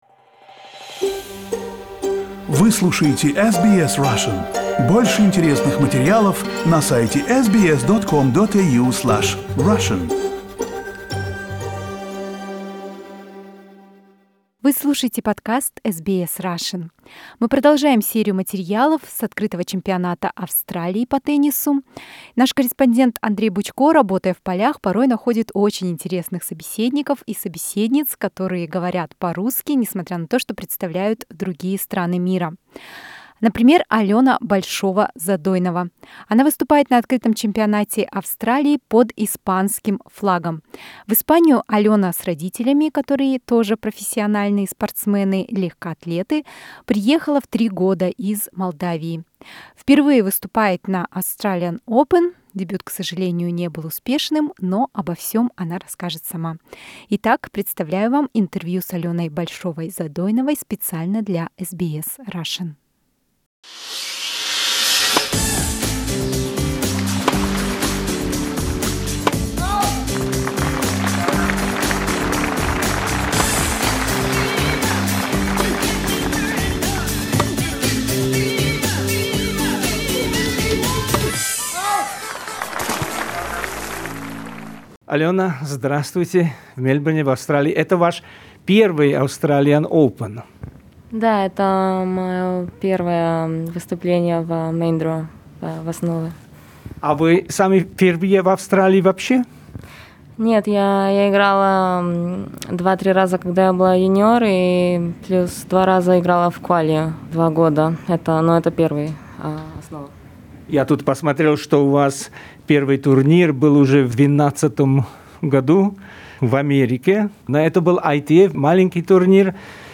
Interview with a Russian-speaking tennis player Alena Bolshova-Zadoinova, Spain, at Australian Open.